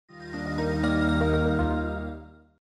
Power Off.mp3